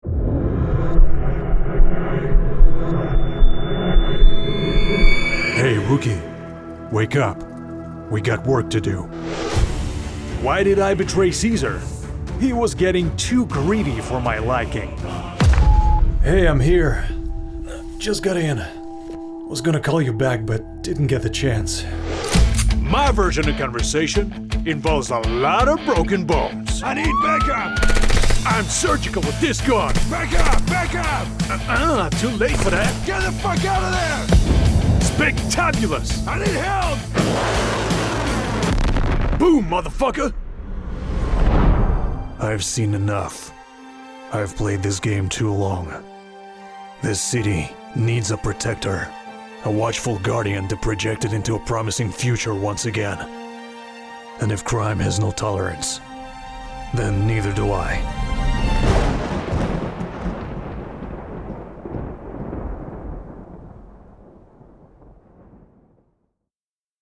Male
A flexible voice that will make your production stand out and bring your characters to life.
Character / Cartoon
Various Characters